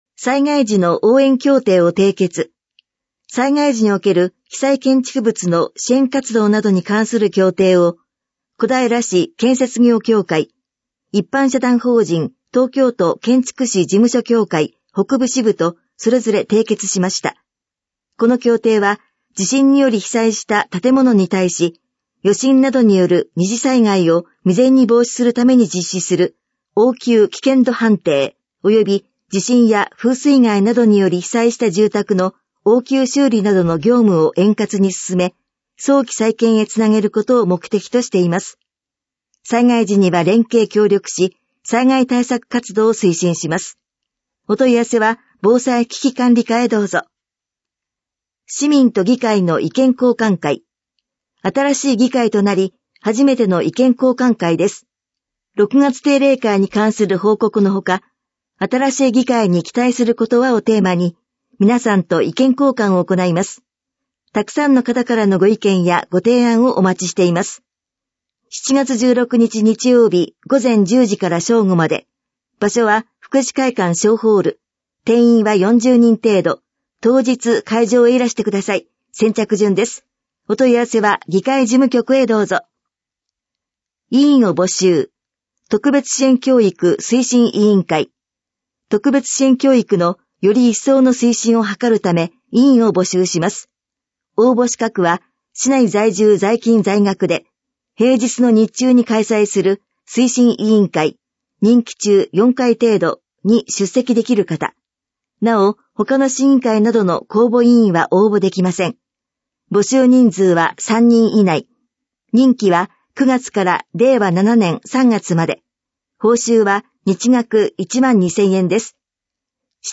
市報音声版「声のたより」市報こだいら2023年7月5日号音声版｜東京都小平市公式ホームページ